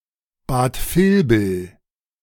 Bad Vilbel (German pronunciation: [baːt ˈfɪlbl̩]
De-Bad_Vilbel.ogg.mp3